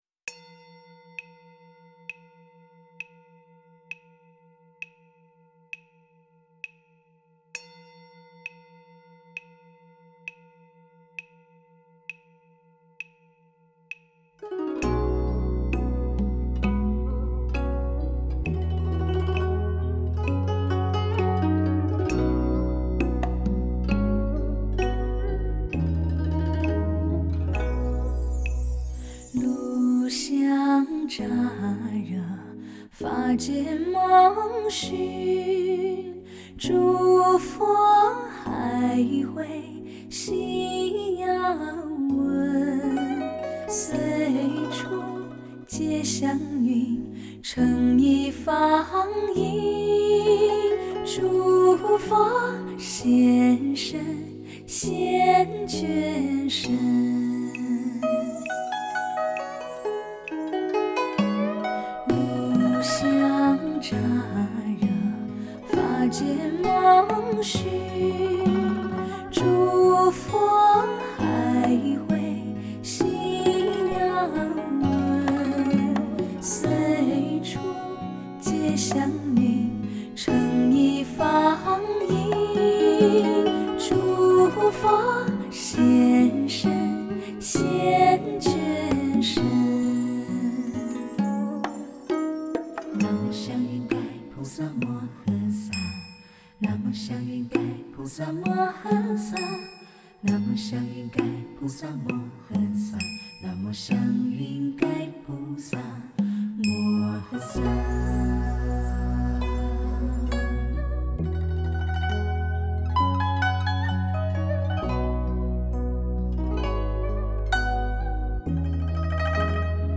茫茫人海琴弦无边天籁梵音佛光普照